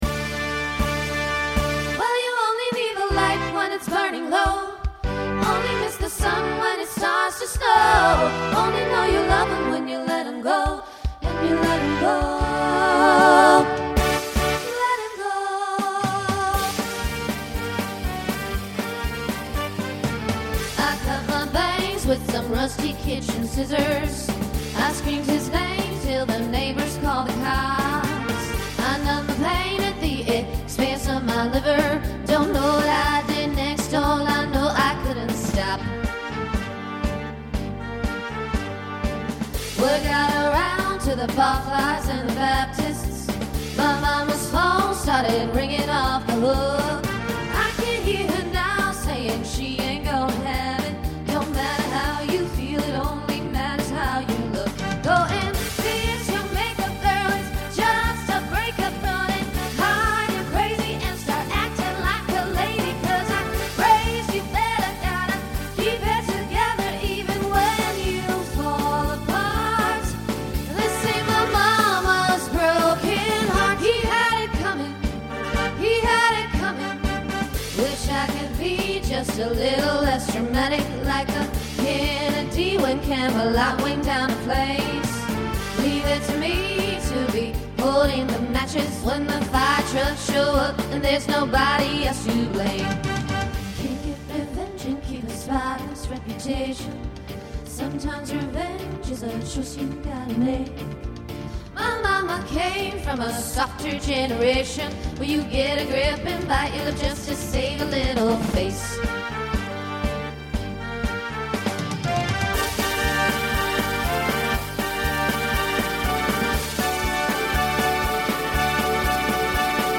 Voicing SSA Instrumental combo Genre Broadway/Film , Country
Mid-tempo